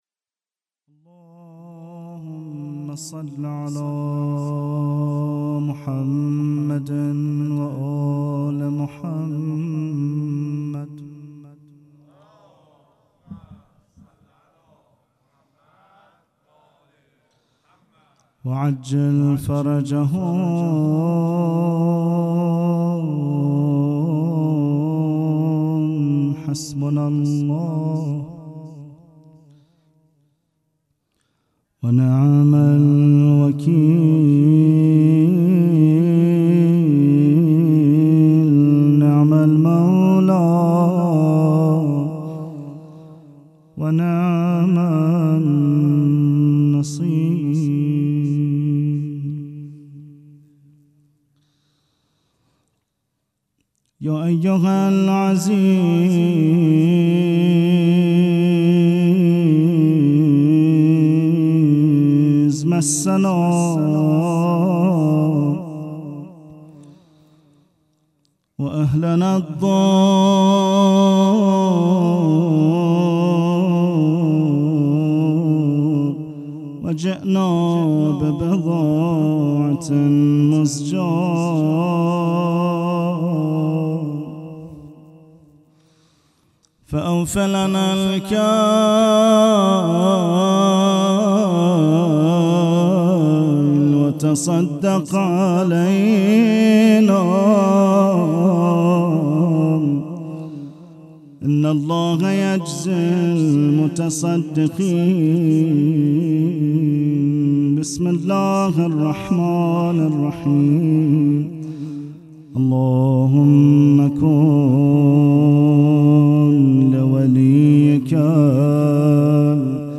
قرائت زیارت وارث
مراسم شب اول ولادت سرداران کربلا